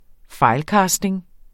Udtale [ ˈfɑjlˌkɑːsdeŋ ]